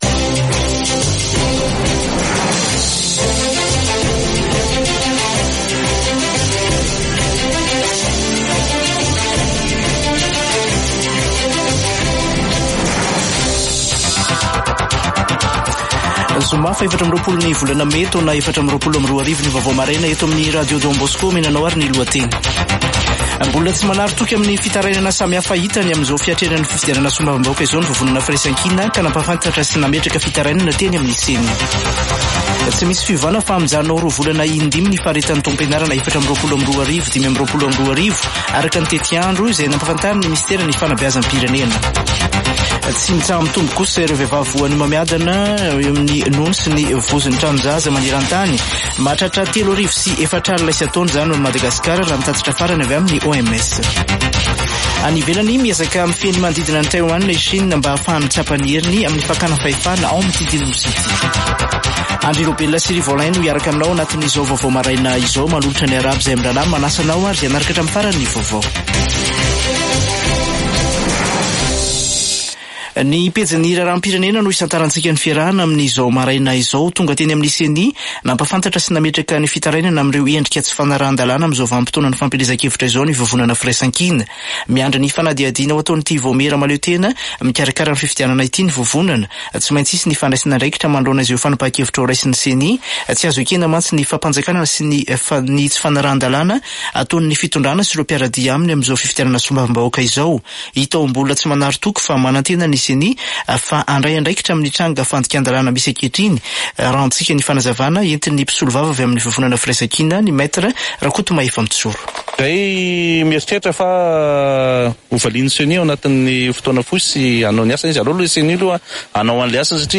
[Vaovao maraina] Zoma 24 mey 2024